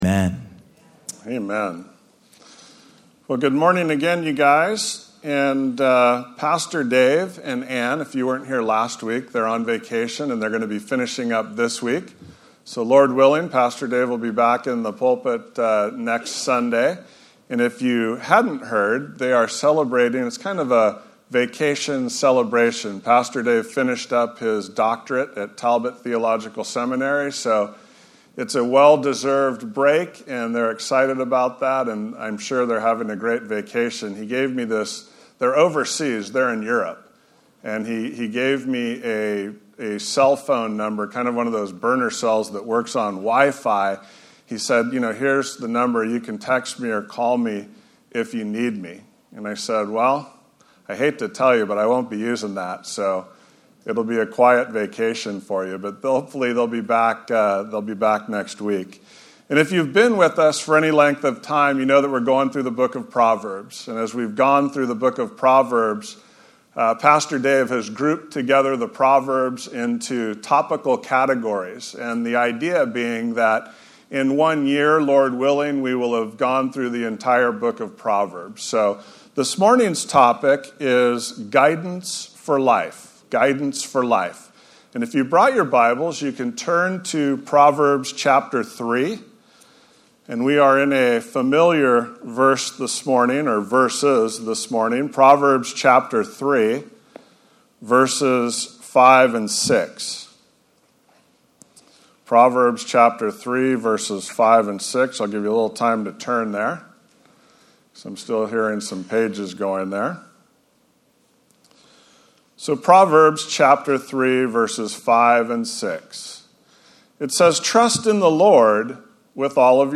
Messages | Pacific Hills Calvary Chapel | Orange County | Local Church